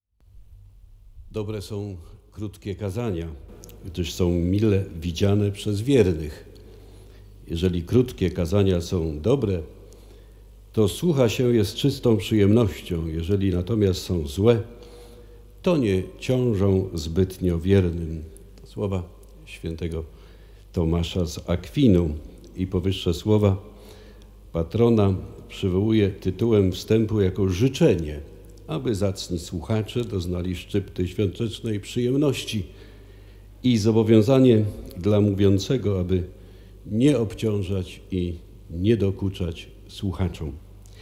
Swoje słowo bp Lityński rozpoczął od zacytowania św. Tomasza z Akwinu, życząc dobrego słuchania wszystkim obecnym w katedrze.